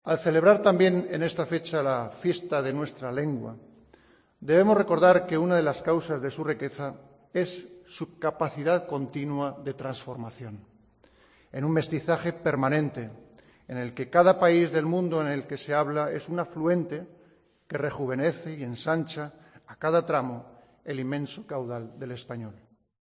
En la ceremonia de entrega del Premio Cervantes al escritor mexicano Fernando del Paso.